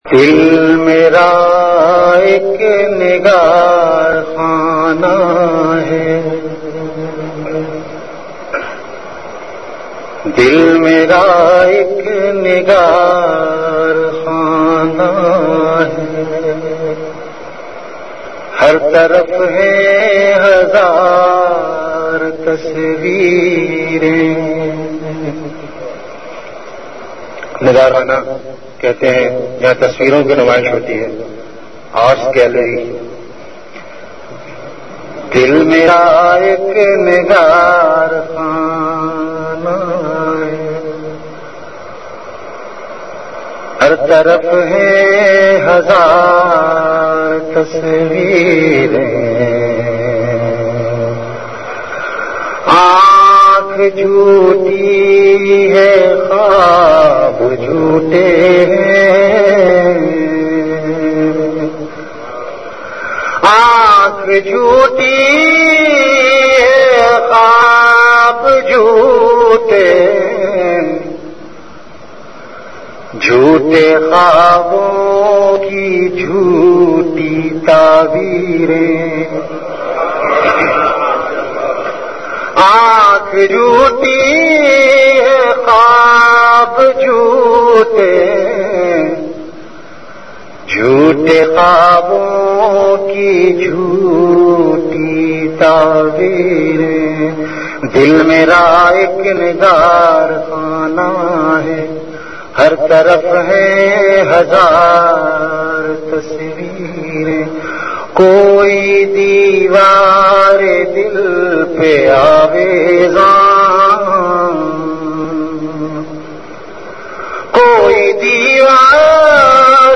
Hamd
CategoryAshaar
Event / TimeAfter Isha Prayer